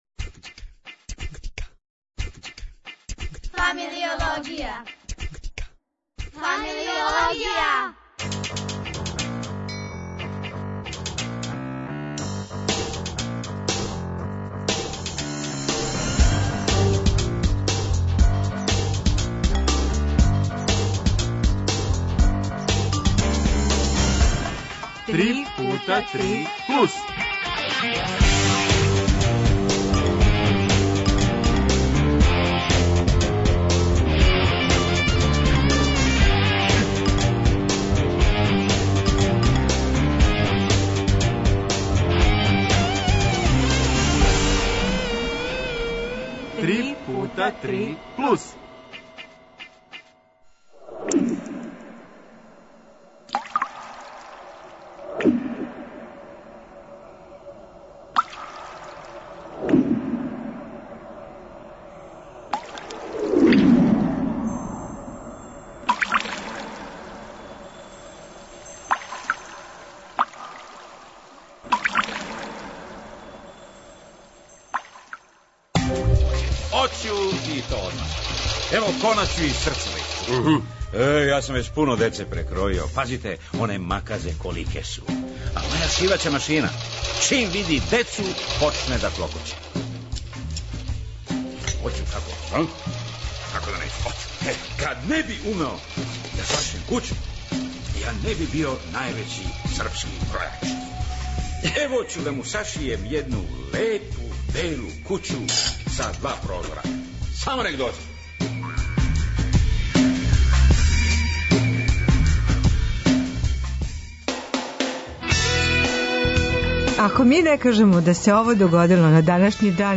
Ту су и наша деца, чланови Дечје драмске групе РТС-а.